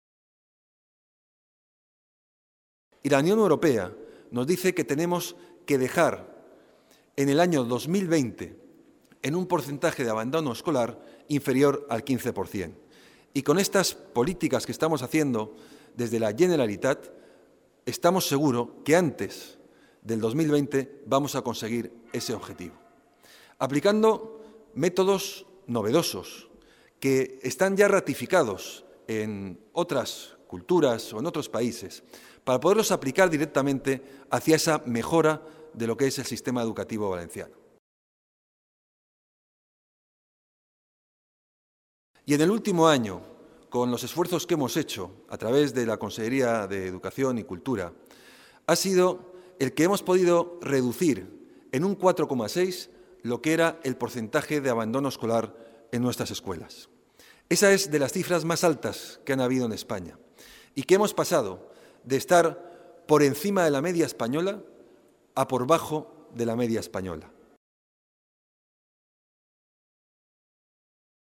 Fabra ha realizado estas declaraciones tras presidir en el Palau de la Generalitat la firma del convenio entre el presidente de Bankia, José Ignacio Gorigoizarri, y la consellera de Educación, Cultura y Deporte, María José Catalá, por el que la entidad bancaria financiará con un millón de euros anuales el coste de las clases de música que impartirán las sociedades musicales de la Comunitat a alumnos con riesgo de padecer fracaso escolar.